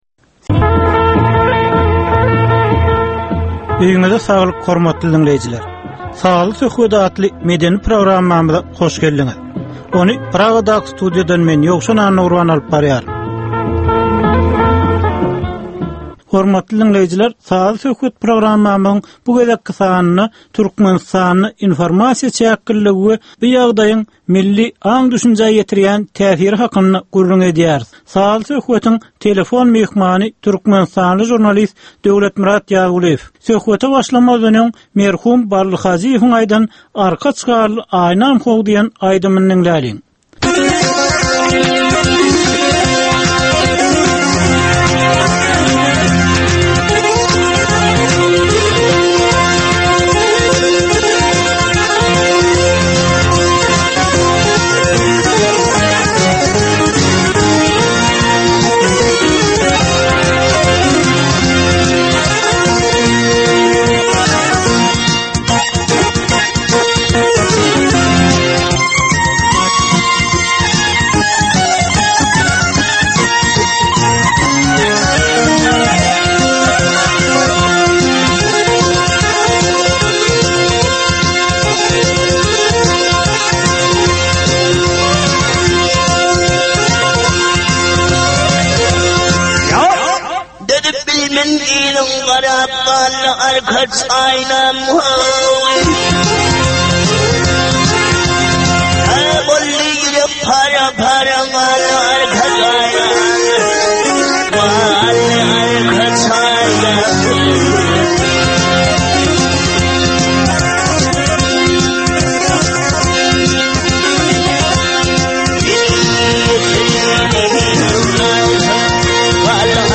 Türkmenistanyň käbir aktual meseleleri barada sazly-informasion programma.